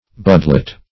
Search Result for " budlet" : The Collaborative International Dictionary of English v.0.48: Budlet \Bud"let\, n. [Bud + -let.]